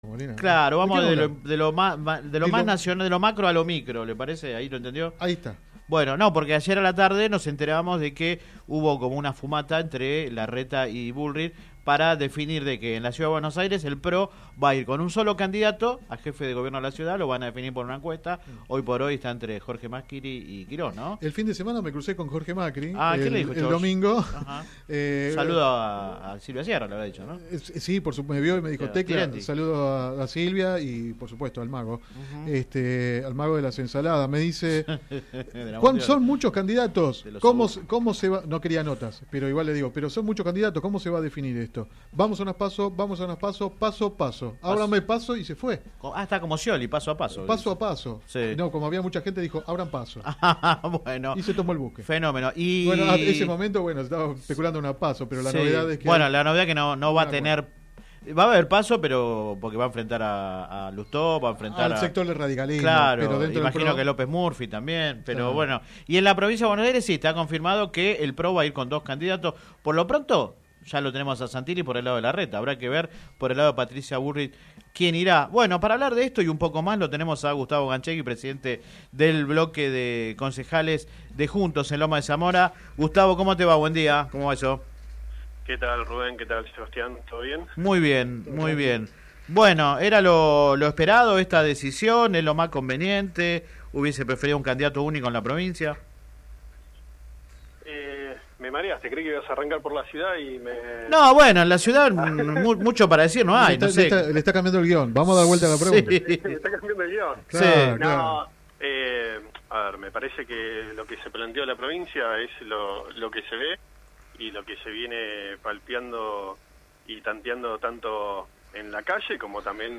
Al mismo tiempo ratificó su precandidatura a intendente y lanzó críticas contra la conducción del Concejo Deliberante. El dirigente del PRO habló en el programa radial Sin Retorno (lunes a viernes de 10 a 13 por GPS El Camino FM 90 .7 y AM 1260).